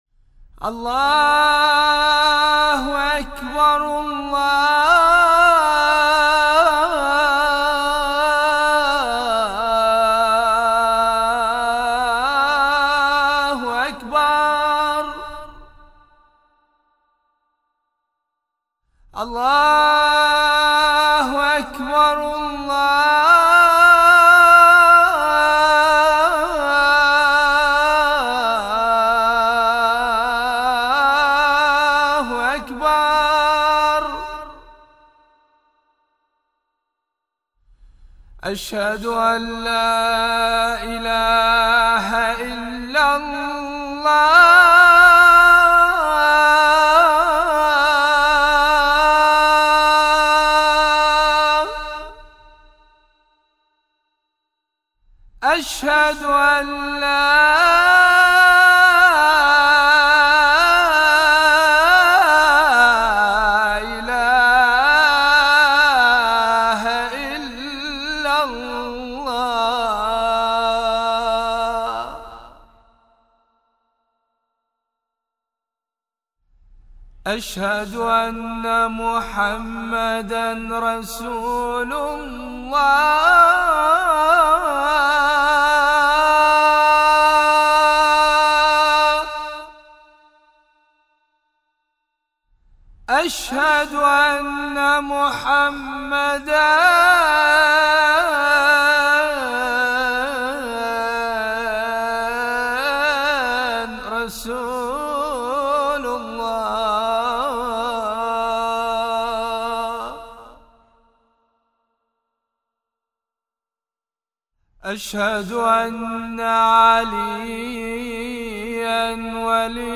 الأذان – بصوت الرادود